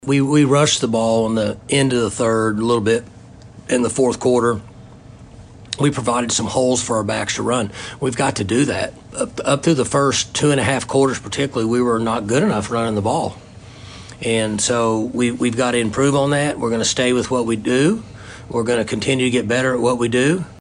Head coach Mike Gundy talks about the difference between the Cowboy offense early and late in the game.